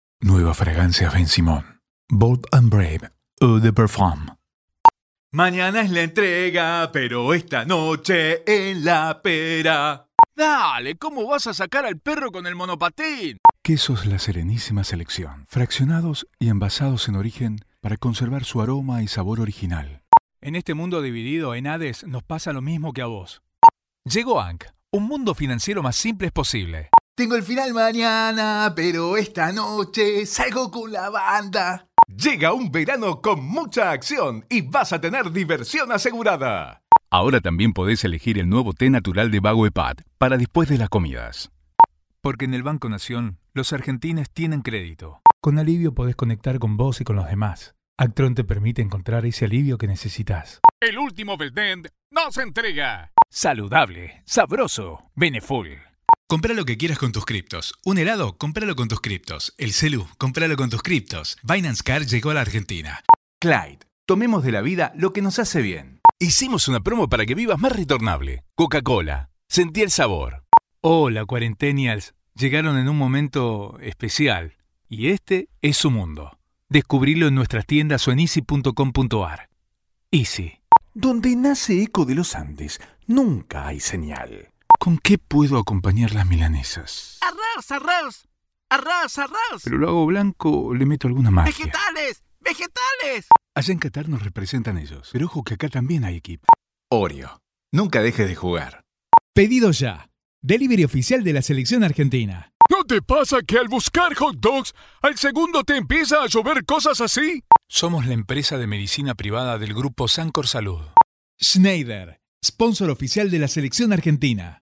Adult male voice, warm and trustworthy, with clear diction and strong on-mic presence.
Natural Speak